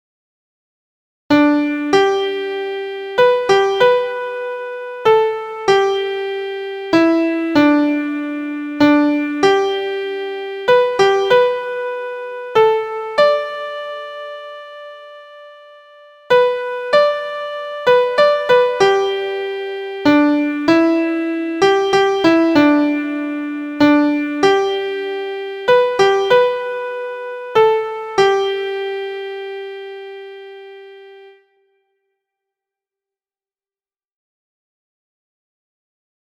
Strophic: all verses sung to the same melody.
Melody centered around the fifth, So.
• Key: G Major
• Time: 3/4
• Form: through composed
• Pitches: beginners: So La Do Re Mi So – pentatonic scale